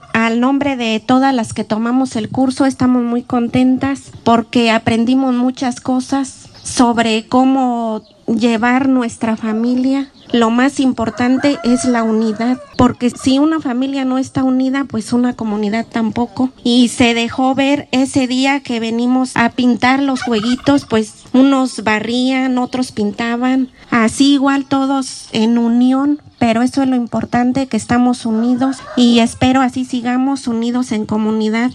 AudioBoletines
Lorena Alfaro García – Presidenta Municipal